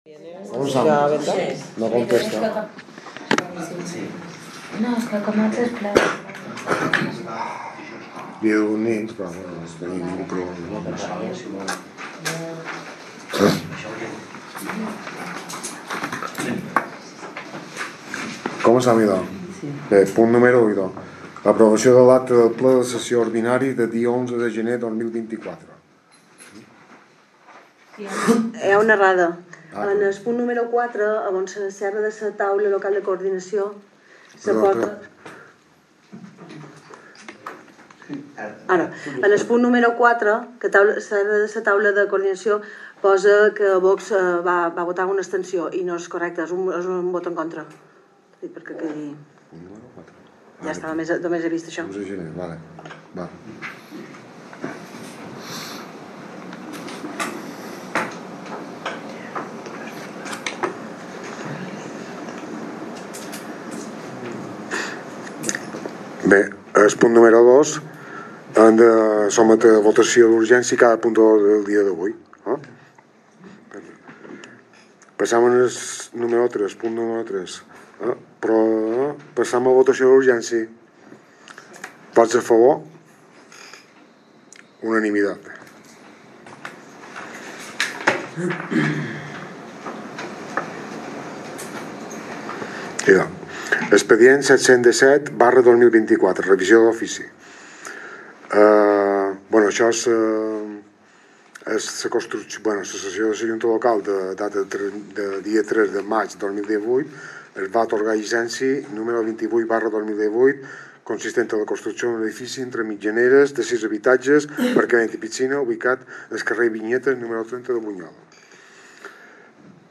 Sessió del Ple de l'Ajuntament de Bunyola que es celebrarà el proper dijous dia 7 de març a les 19.00 hores, a la Sala Plenaris Ajuntament.